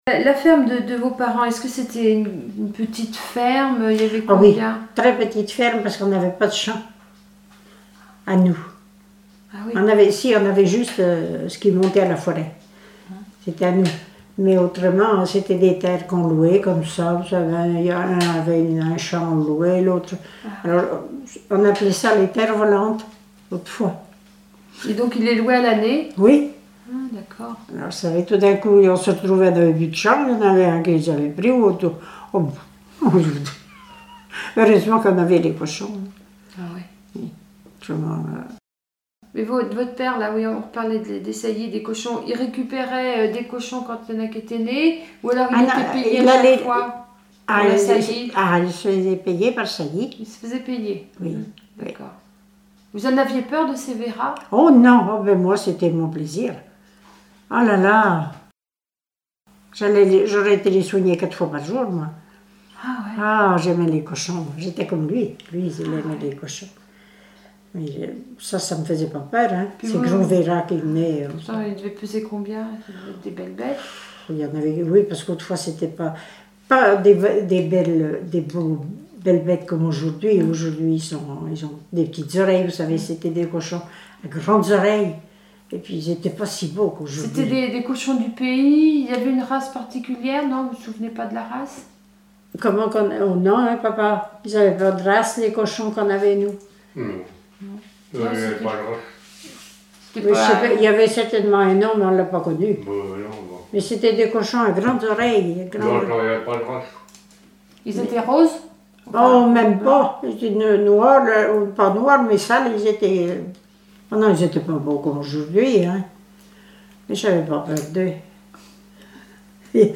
Témoignages sur la vie à la ferme
Catégorie Témoignage